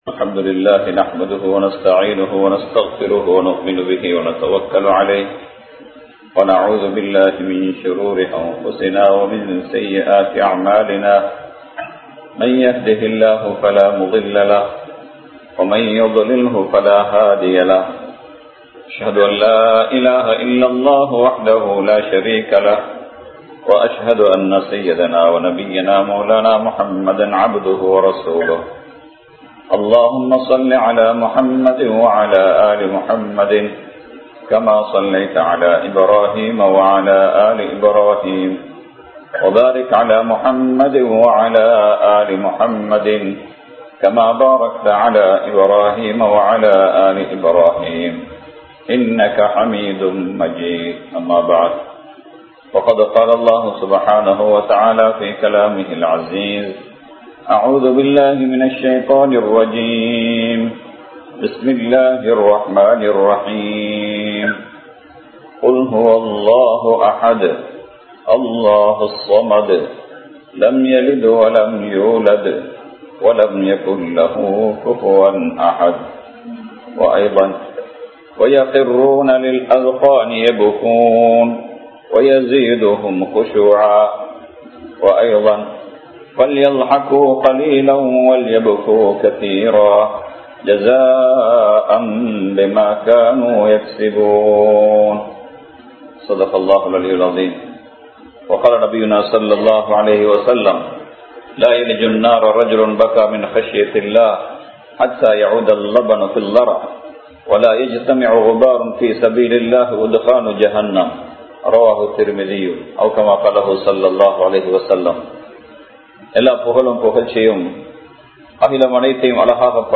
அல்லாஹ்வின் அச்சத்தால் அழுத கண்ணீர் | Audio Bayans | All Ceylon Muslim Youth Community | Addalaichenai